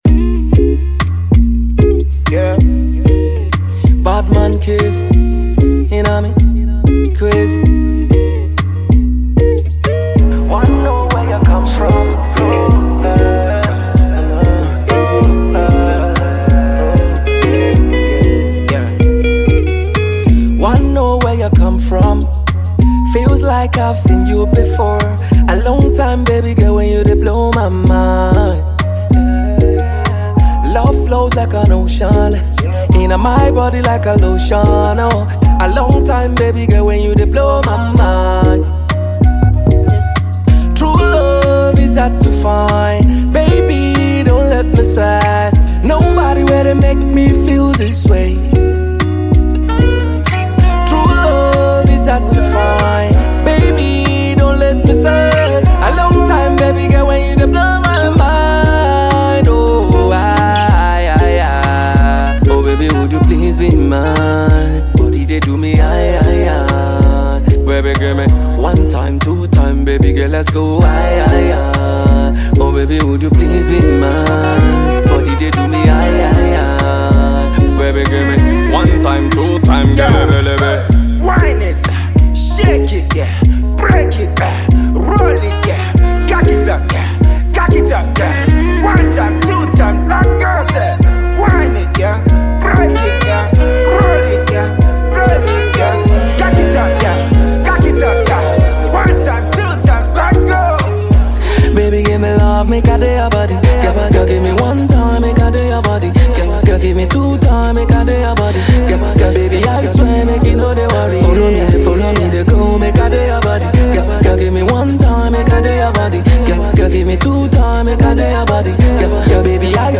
reggae infused love song